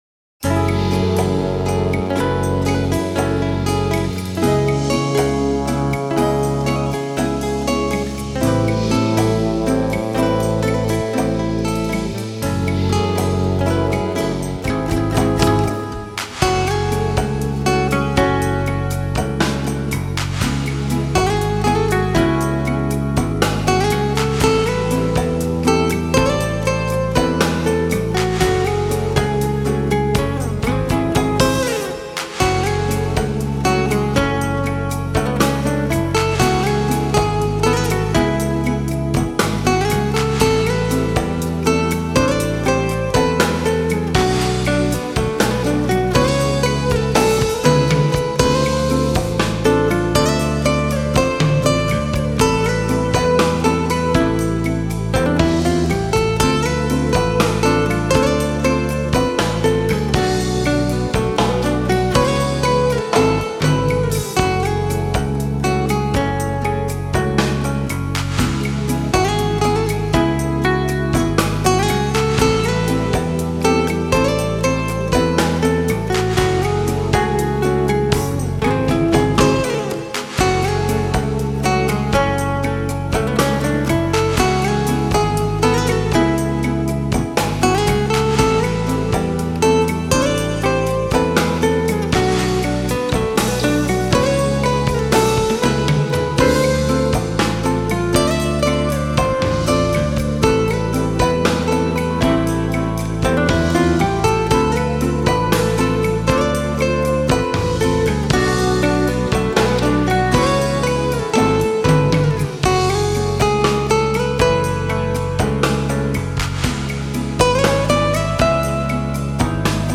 Chicago based jazz guitarist and composer.